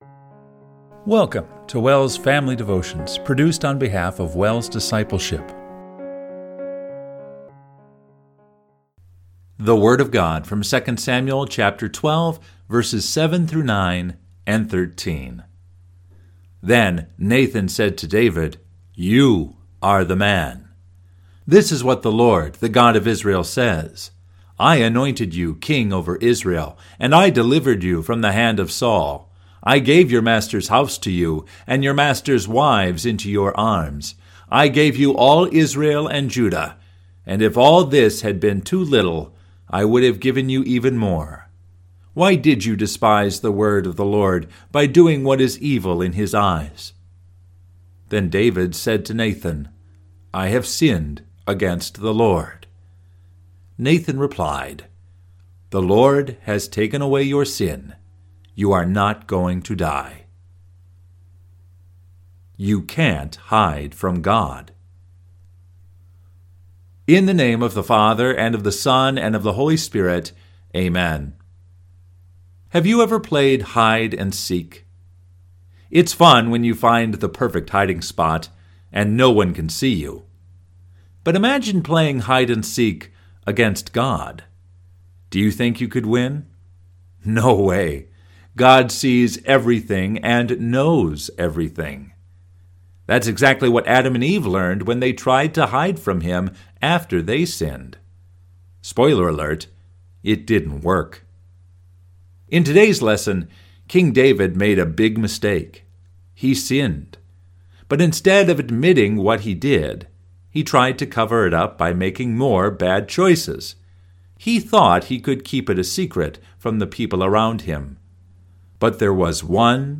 Family Devotion – March 5, 2025